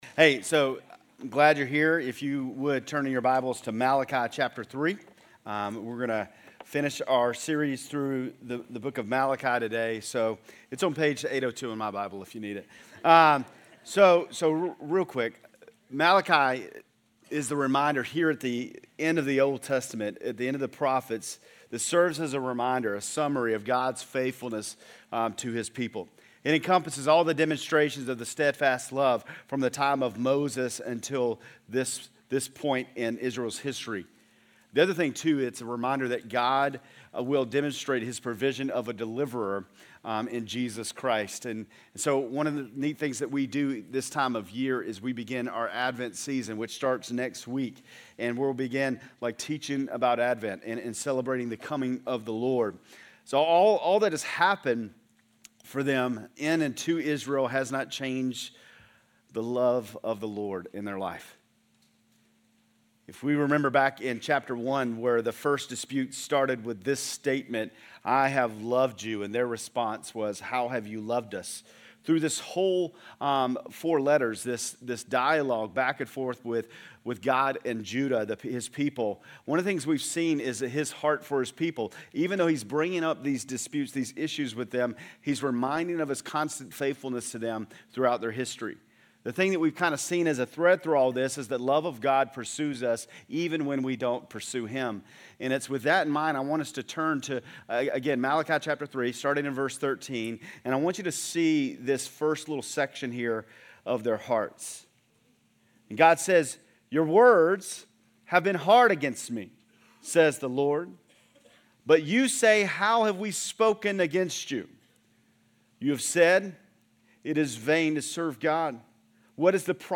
GCC-Lindale-November-20-Sermon.mp3